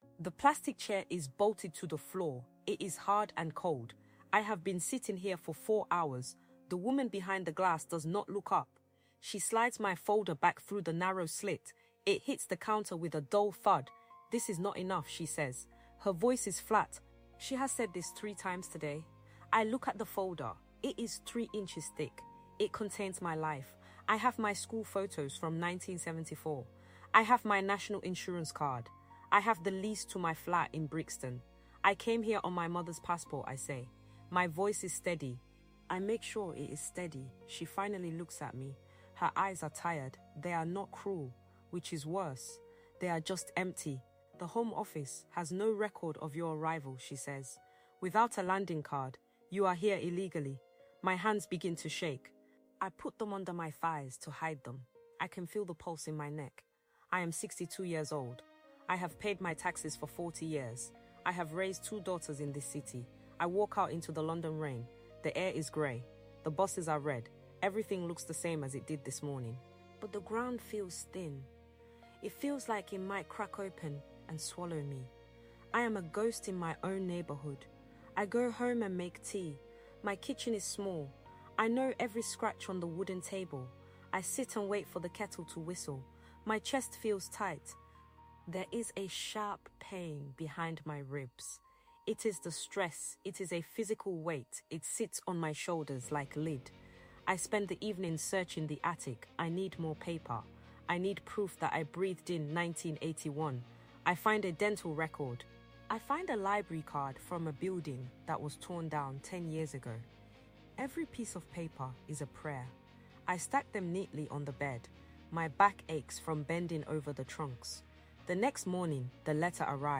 This episode of THE TRIALS OF WOMAN explores the harrowing reality of the Windrush Scandal through the eyes of a woman who spent forty years building a life in London, only to be told she no longer exists. "The Paper Trail to Nowhere" is an immersive, first-person journey into the heart of a hostile environment where a lifetime of work, family, and contribution is reduced to a missing landing card.
This narrative podcast highlights the systemic failure and the "Body-Failure" caused by the relentless stress of proving one's own humanity to an indifferent bureaucracy.